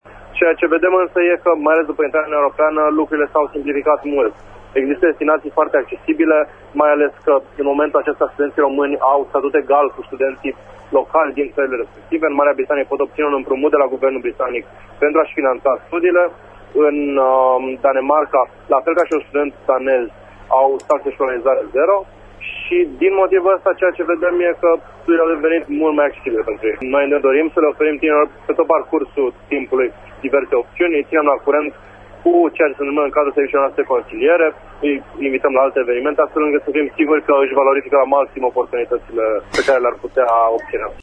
extras emisiunea „Pulsul zilei”